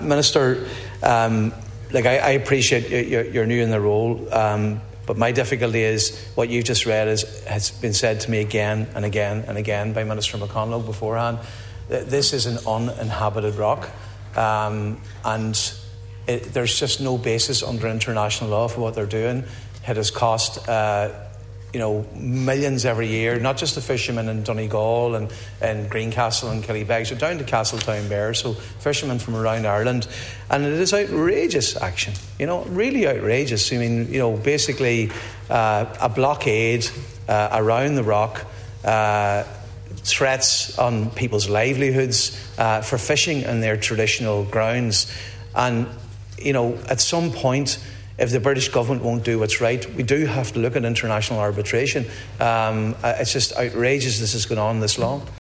However, Deputy Mac Lochlainn says the fishing industry in Donegal and across the country is being negatively affected due to the lack of action: